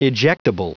Prononciation du mot ejectable en anglais (fichier audio)
Prononciation du mot : ejectable